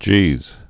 (jēz)